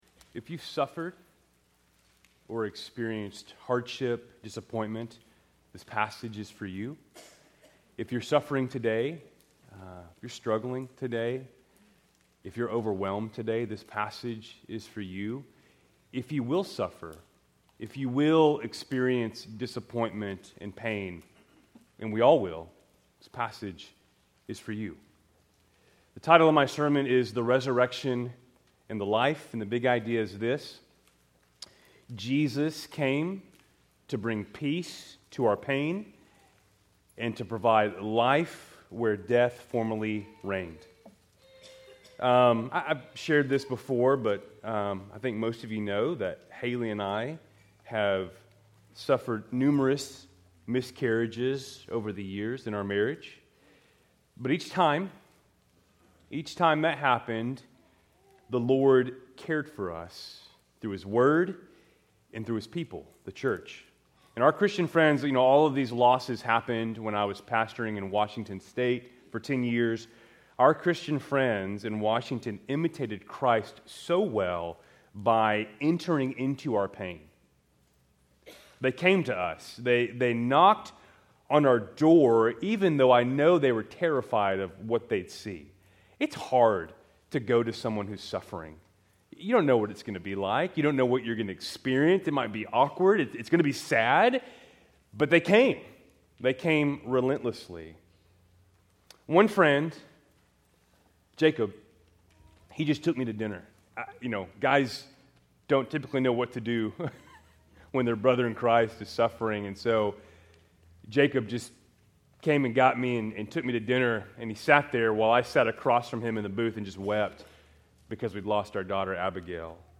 Keltys Worship Service, March 9, 2025